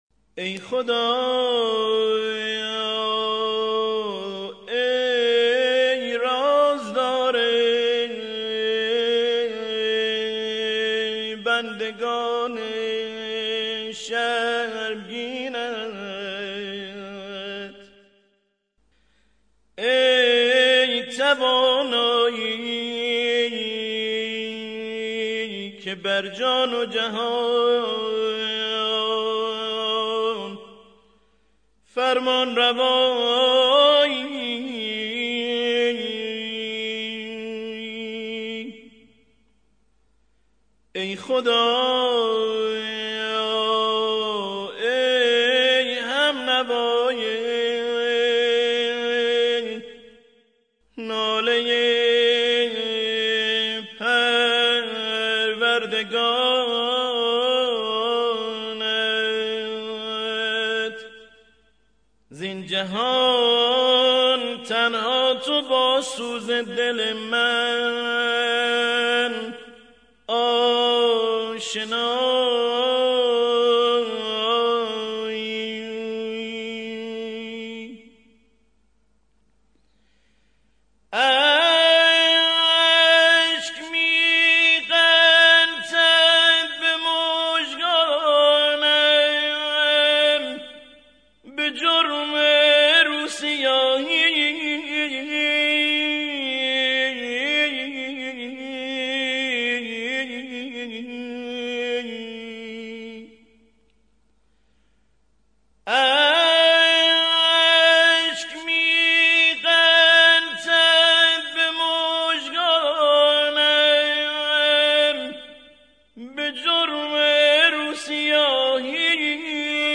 مناجات با خدا